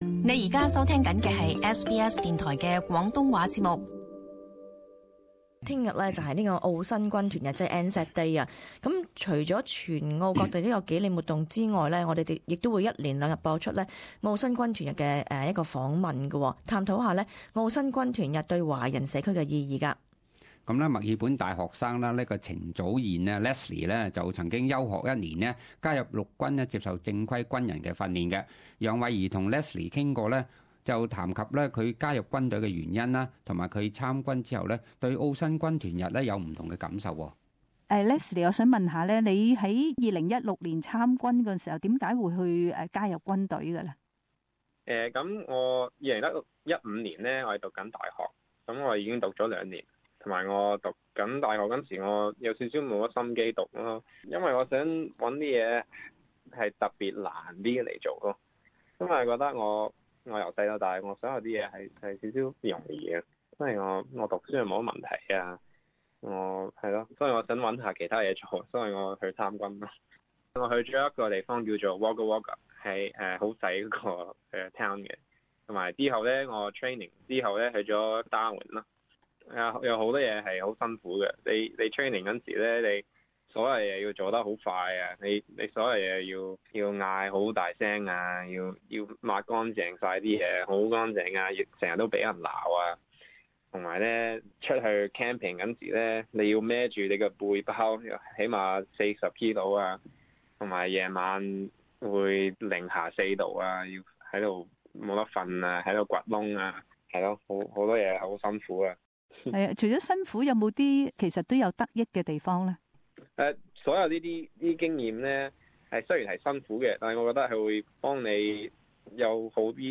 【專訪】